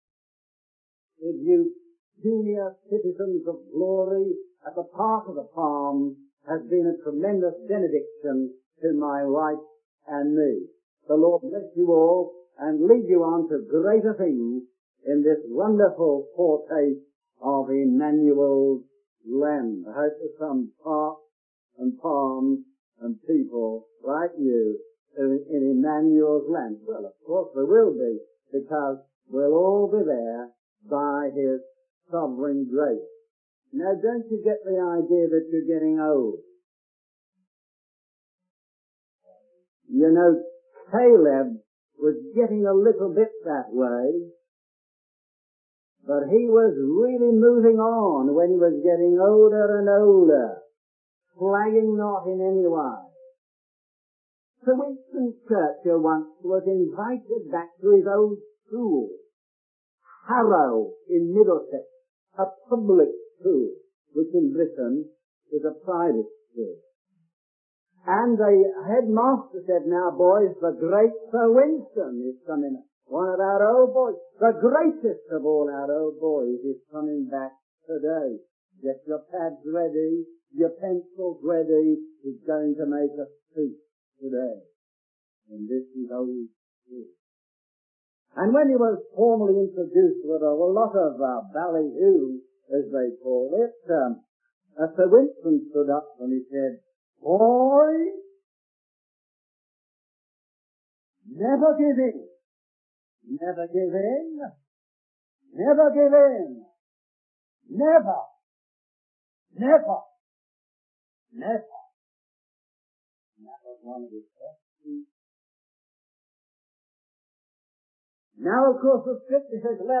In this sermon, the preacher emphasizes the importance of opening one's heart to the Lord and allowing Him to work in one's life. The preacher encourages the congregation to fully surrender to God and receive His blessings and guidance.